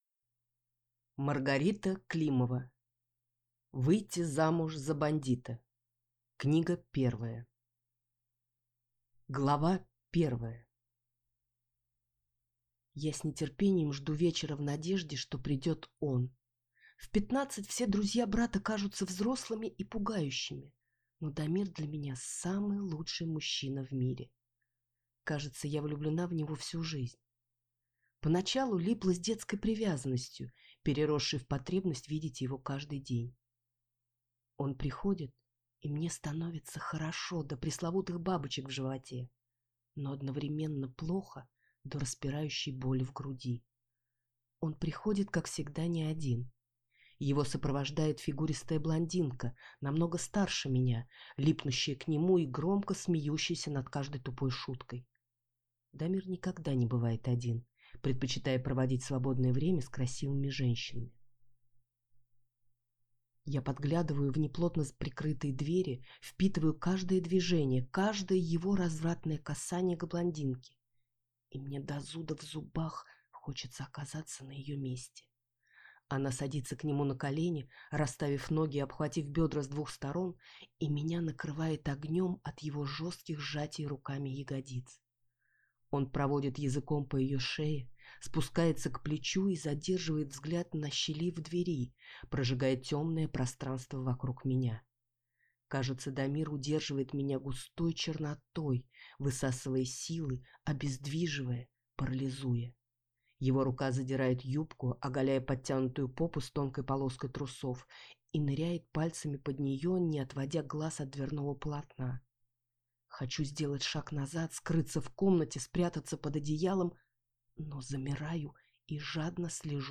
Аудиокнига Выйти замуж за бандита. Книга 1 | Библиотека аудиокниг